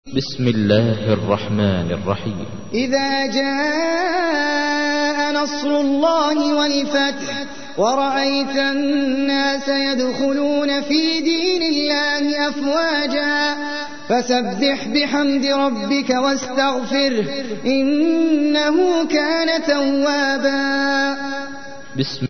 تحميل : 110. سورة النصر / القارئ احمد العجمي / القرآن الكريم / موقع يا حسين